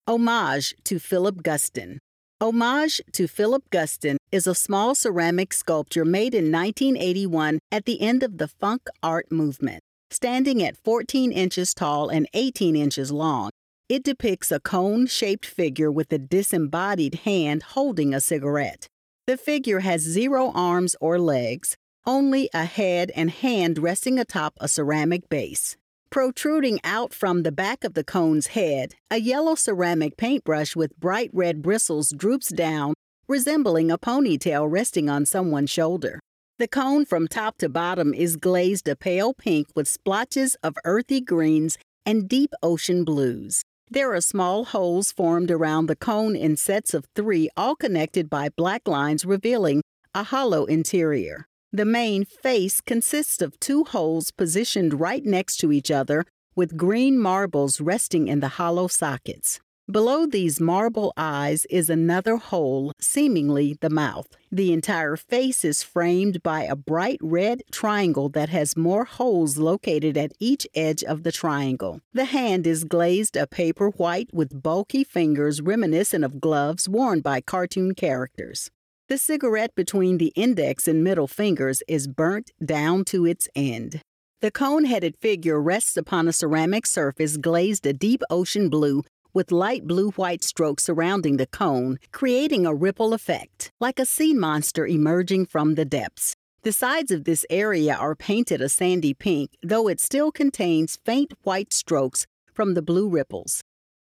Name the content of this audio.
Audio Description (01:44)